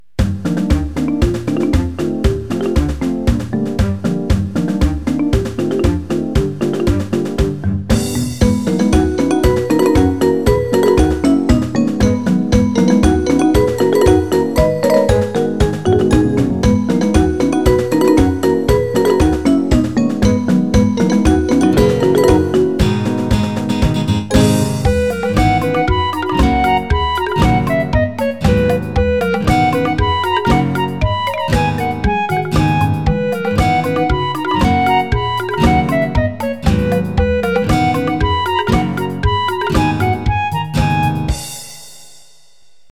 フィドルと迷った挙句にメロディはビブラフォンになりました。